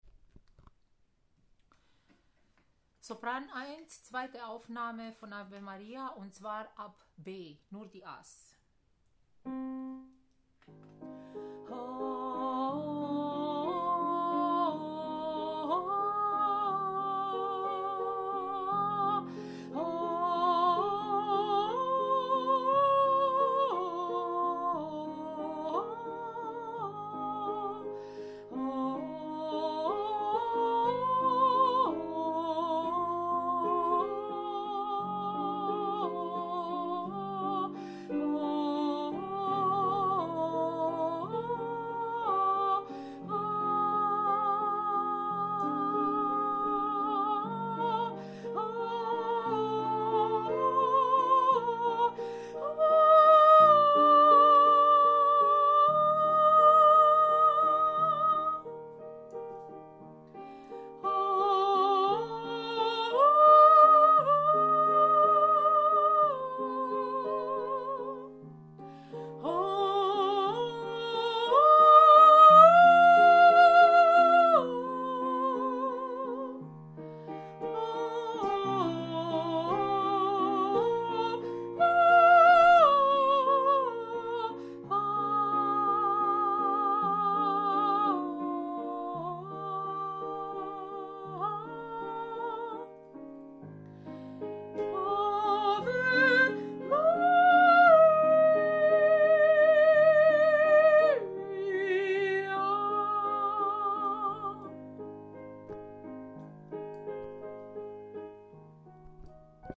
Ave Maria – Sopran 1- TeilB